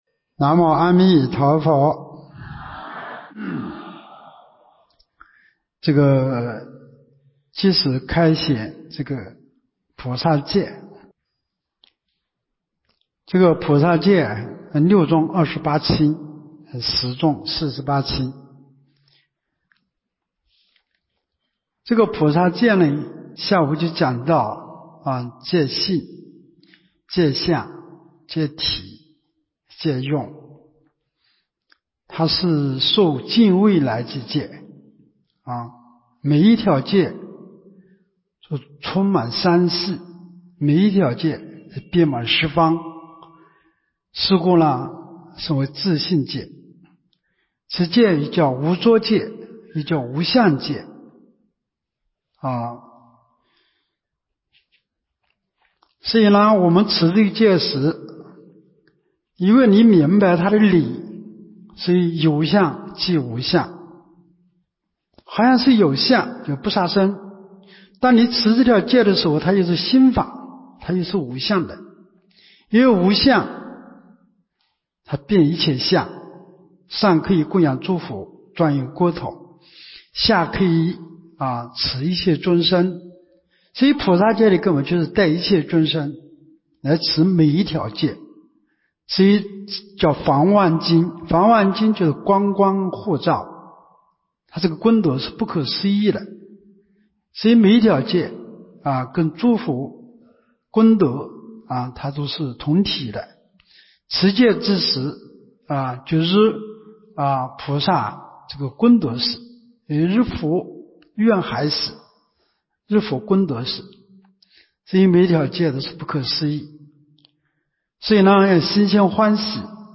2024年陆丰学佛苑三皈五戒菩萨戒开示（2）